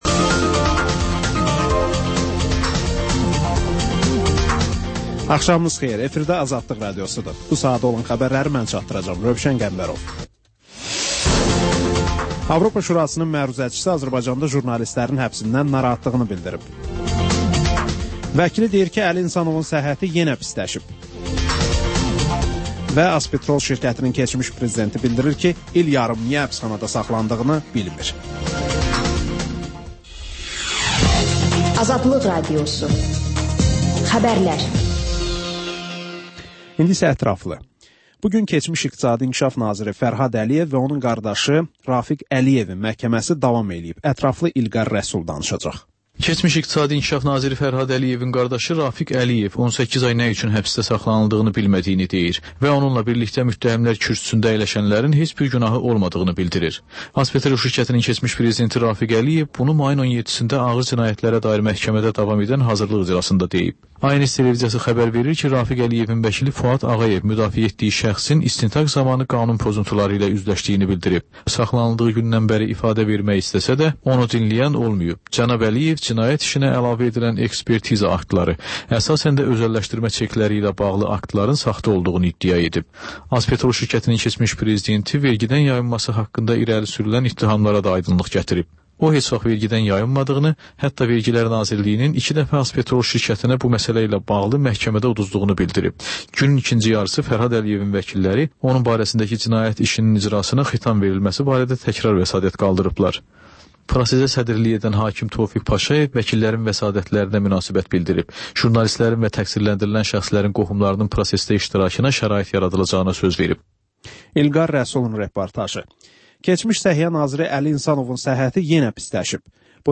Xəbərlər, müsahibələr, hadisələrin müzakirəsi, təhlillər, sonda TANINMIŞLAR verilişi: Ölkənin tanınmış simalarıyla söhbət